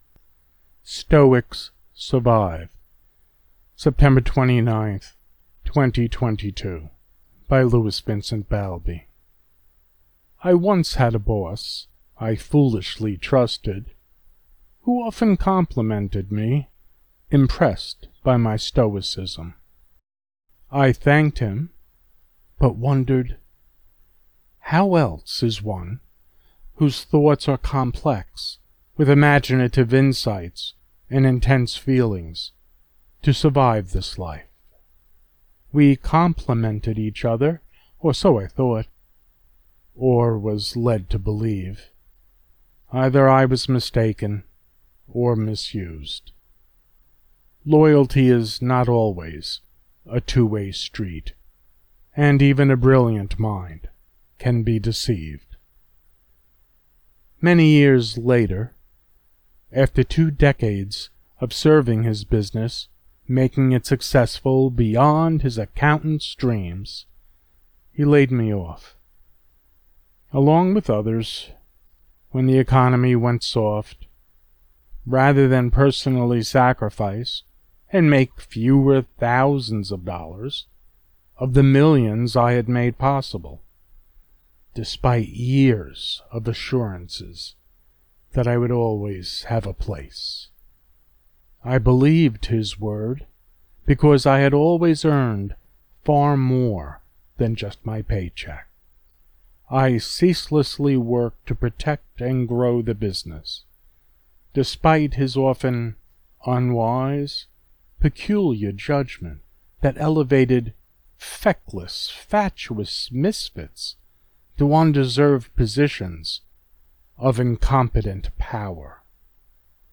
Stoics Survive Poem